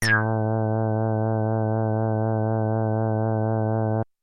标签： MIDI-速度-52 A4 MIDI音符-69 罗兰-JX-8P 合成器 单票据 多重采样
声道立体声